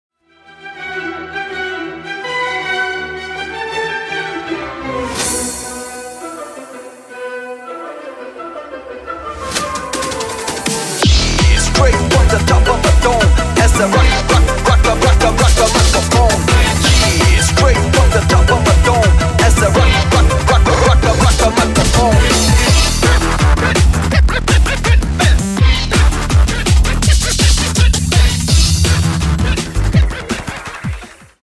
Рингтоны Ремиксы » # Рингтоны Электроника